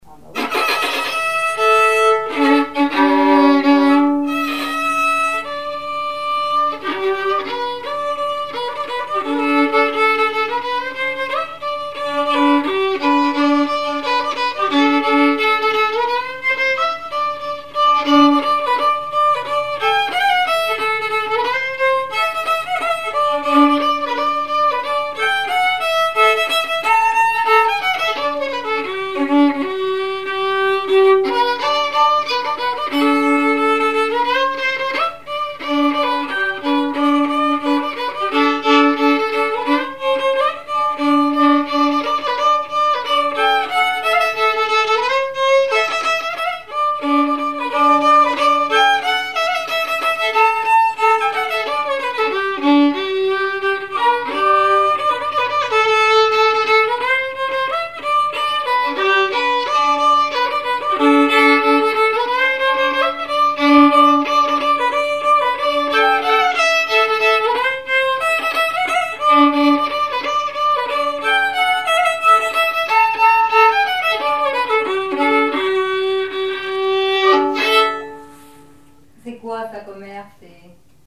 Chants brefs - A danser
scottich trois pas
Pièce musicale inédite